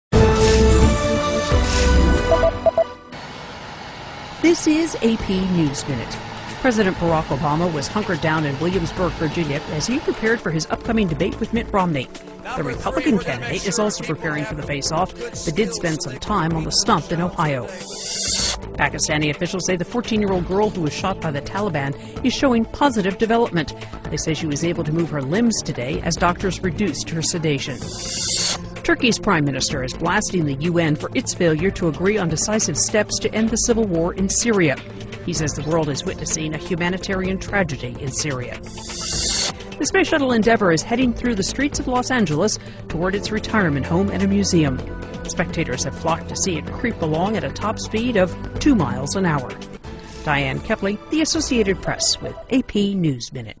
在线英语听力室美联社新闻一分钟 AP 2012-10-16的听力文件下载,美联社新闻一分钟2012,英语听力,英语新闻,英语MP3 由美联社编辑的一分钟国际电视新闻，报道每天发生的重大国际事件。电视新闻片长一分钟，一般包括五个小段，简明扼要，语言规范，便于大家快速了解世界大事。